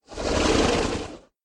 mob / horse / zombie / idle1.ogg